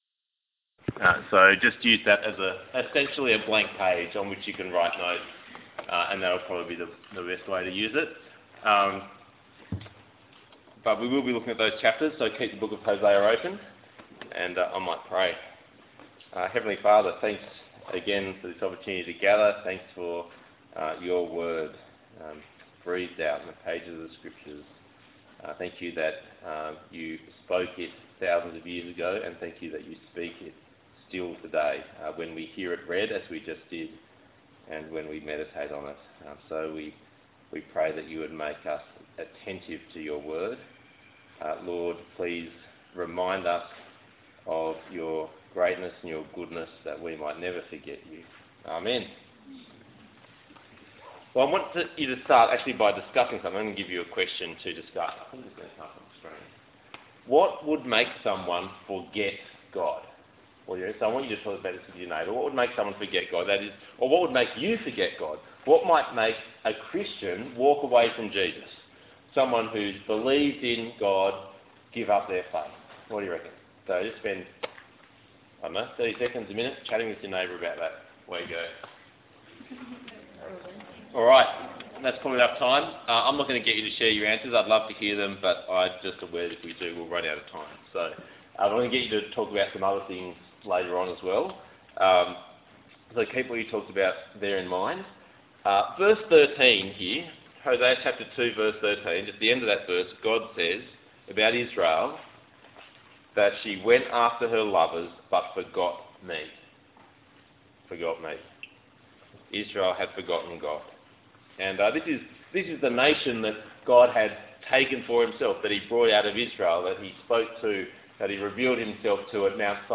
Hosea Passage: Hosea 2:2-3:5 Talk Type: Bible Talk « Hosea Talk 1